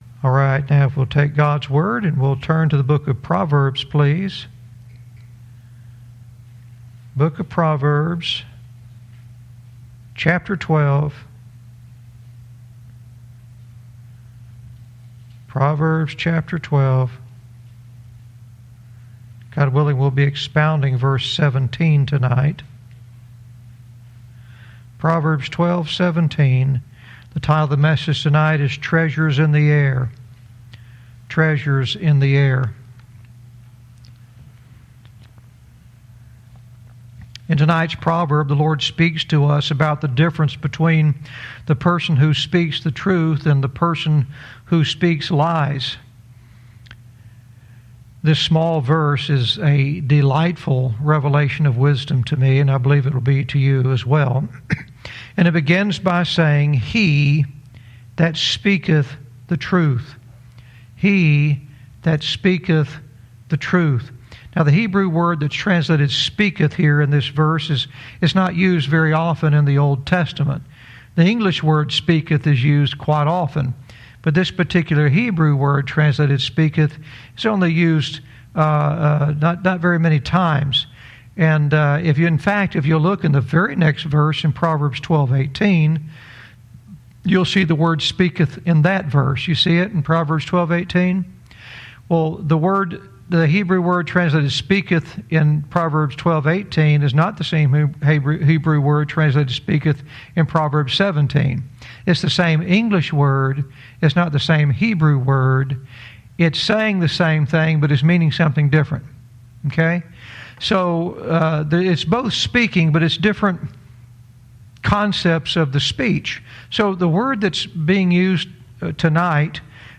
Verse by verse teaching - Proverbs 12:17 "Treasures In the Air"